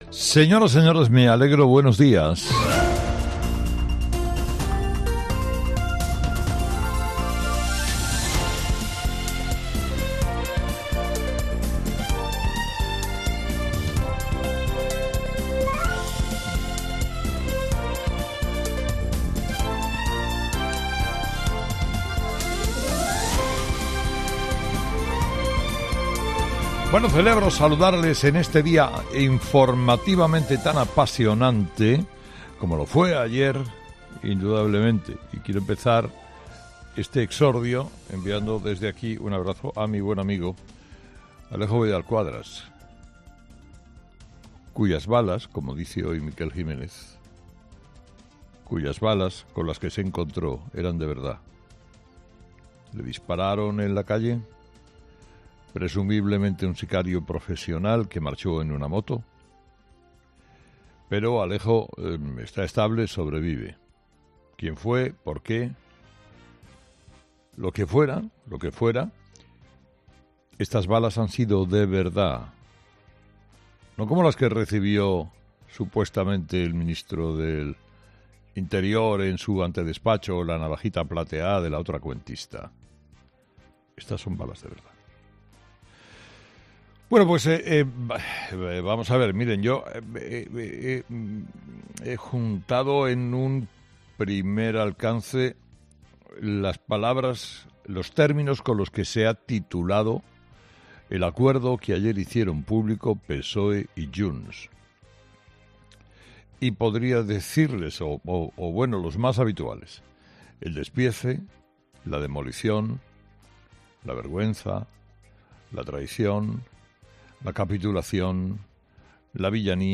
Carlos Herrera repasa los principales titulares que marcarán la actualidad de este viernes 10 de noviembre